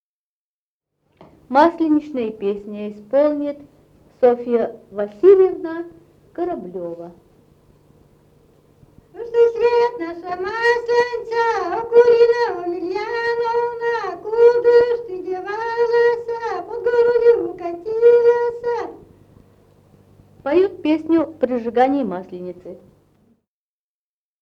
в д. Малата Череповецкого района